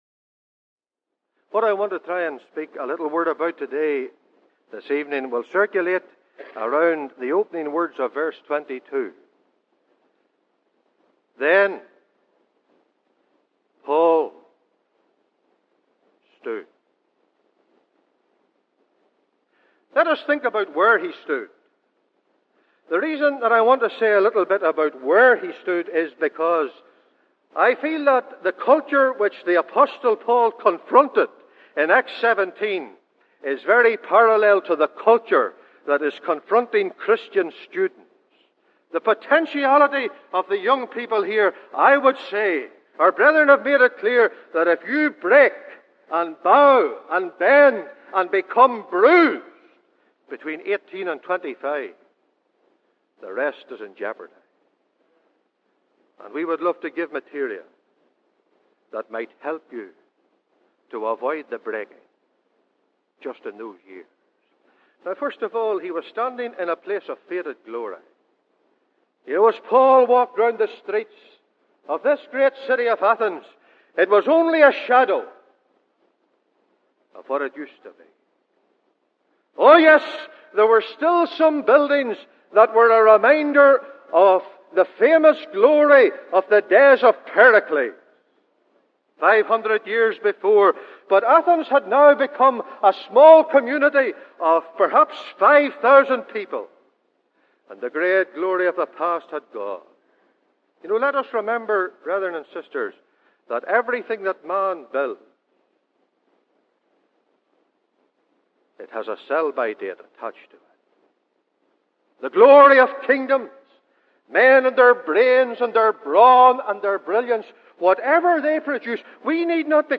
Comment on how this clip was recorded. Where Paul stood, how he stood, what he stood for, and the results. (Recorded in the Ulster Hall, Belfast - Easter Conference)